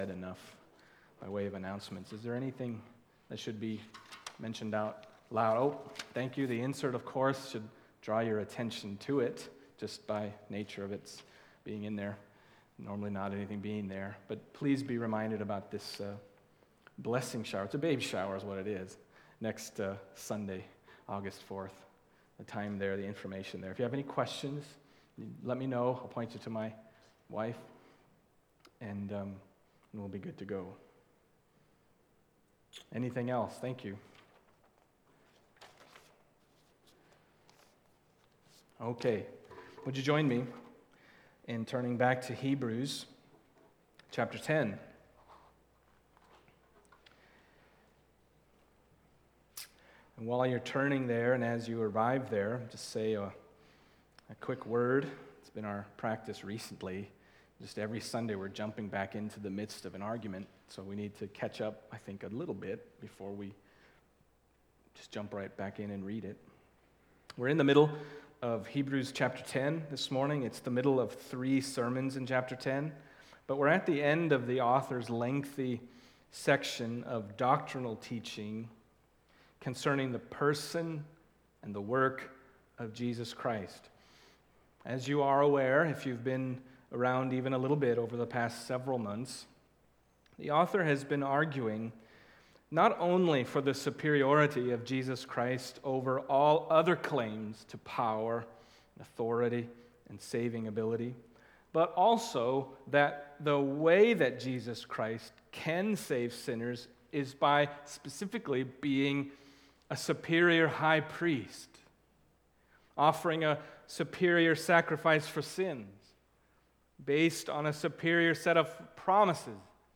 Hebrews 10:26-31 Service Type: Sunday Morning Hebrews 10:26-31 « How Should We Then Live?